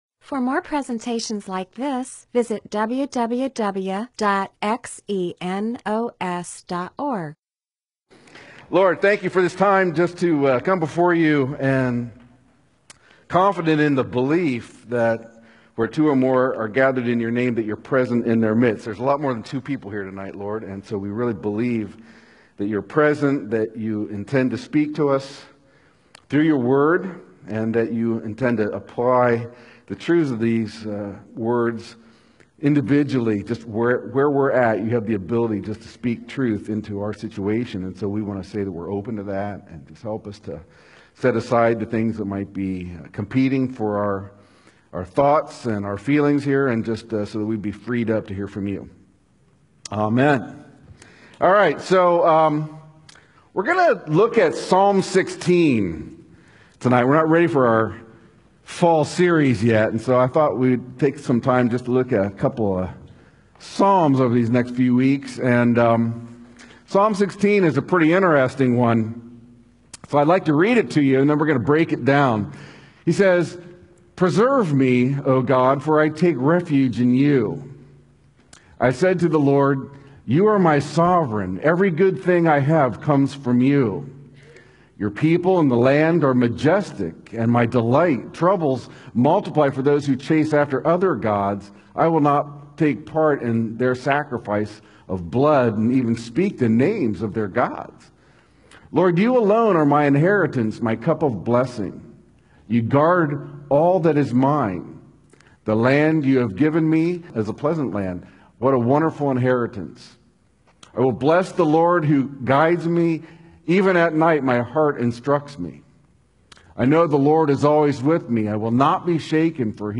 MP4/M4A audio recording of a Bible teaching/sermon/presentation about Psalms 16:1-11.